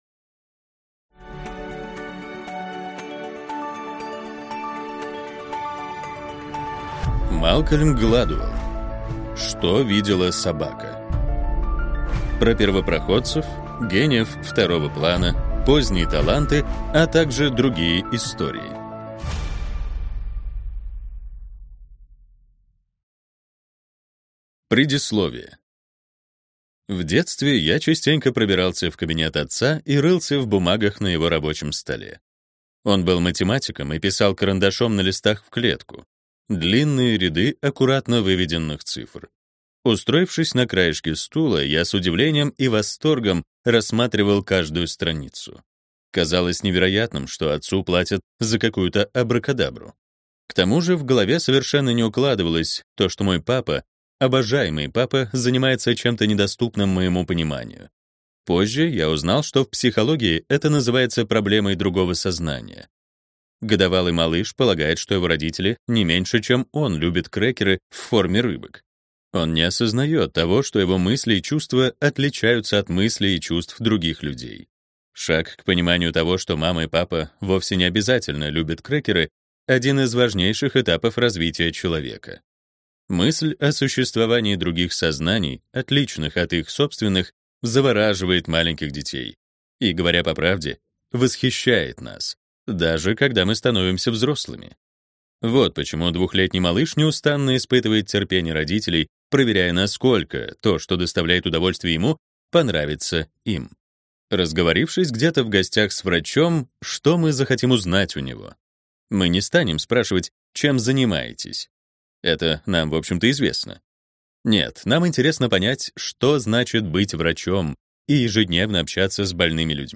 Аудиокнига Что видела собака | Библиотека аудиокниг